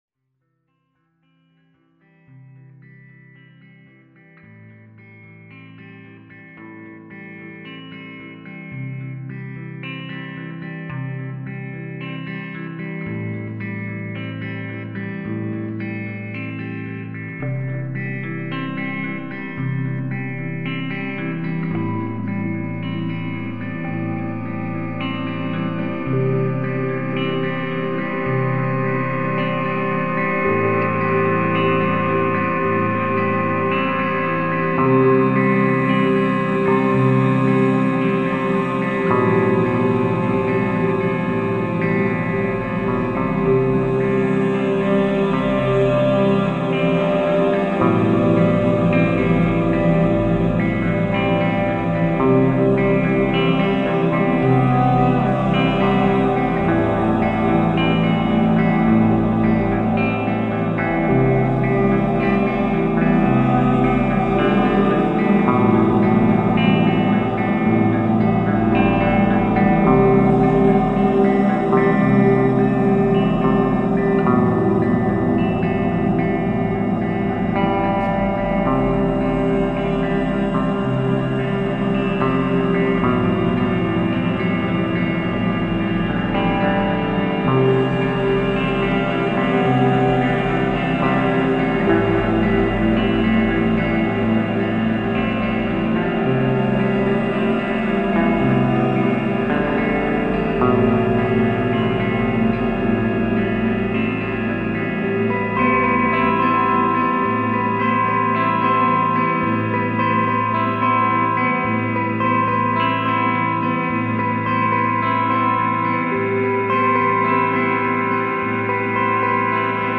Possible Definition: Droning Autumn Sheets
I've noticed this record has kind of "lo-fi" feeling.
Subtle, spatial, and original.